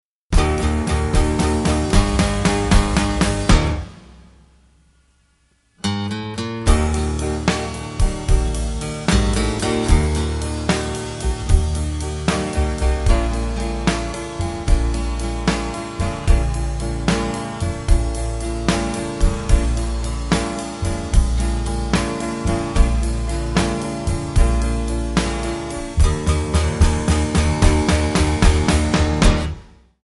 Backing track files: 1950s (275)